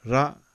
Ra - short vowel sound | 487_14,400